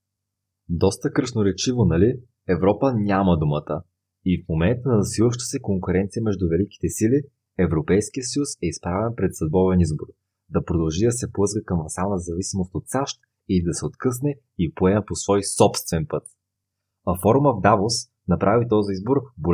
How to make the sound from my cheap microphone better?
Hi, I made a recording, but I think the audio quality is bad.
Sounds like “audio enhancements” have been applied …
Also sounds like you are in a room which has a lot of reverb: acoustic room treatment can be improvised.